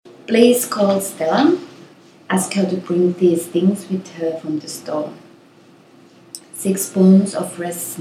Finland-woman-2-tracks.mp3